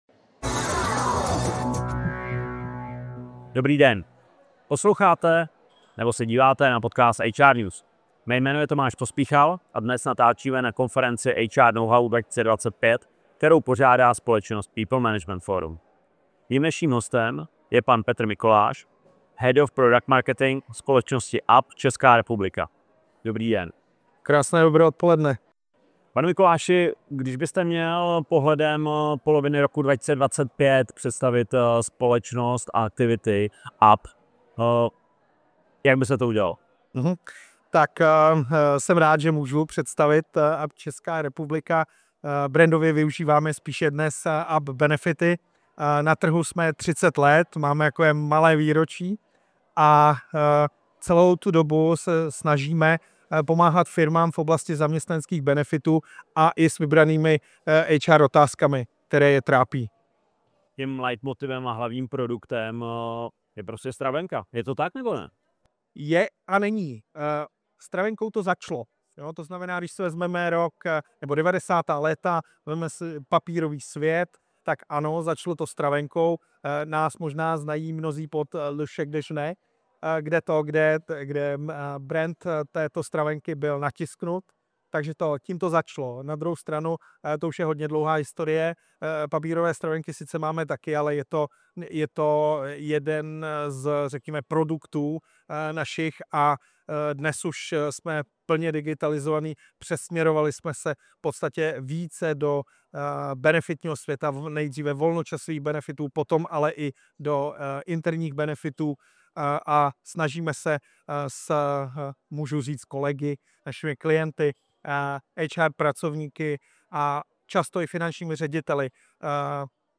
Přehrát zvukový záznam videa V rozhovoru se dozvíte: Up Česká republika (Up Benefity) působí na trhu 30 let a zaměřuje se na digitalizované zaměstnanecké benefity i podporu HR témat.